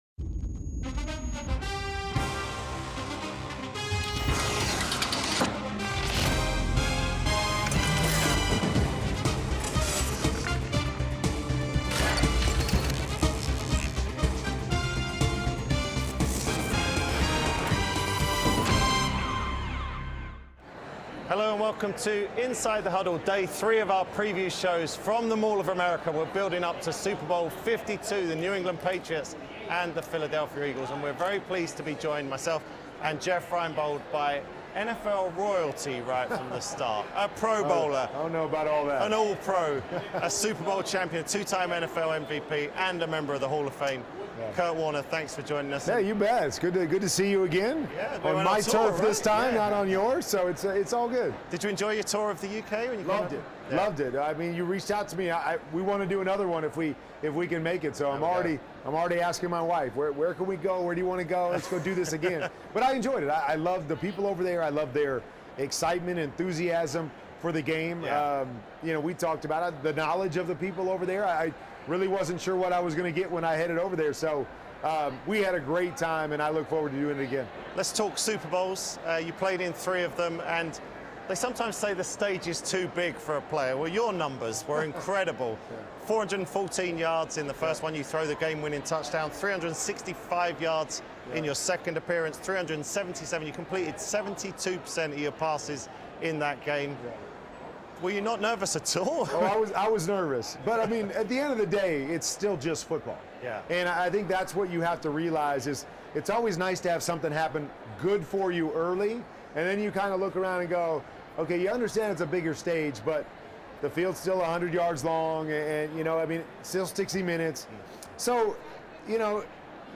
Neil Reynolds and Jeff Reinebold are in Minnesota ahead of Super Bowl LII and are joined by Hall of Fame quarterback Kurt Warner (00:33) and Brian Baldinger (12:10).